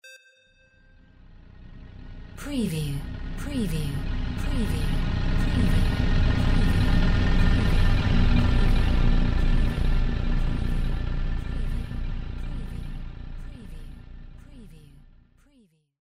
Spaceship passing by 04
Stereo sound effect - Wav.16 bit/44.1 KHz and Mp3 128 Kbps
previewSCIFI_SPACESHIP_PASSBY_WBHD04.mp3